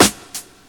Royality free steel snare drum sample tuned to the G# note. Loudest frequency: 1421Hz
• 2000s Hip-Hop Snare Drum G# Key 75.wav